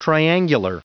Prononciation du mot triangular en anglais (fichier audio)
Prononciation du mot : triangular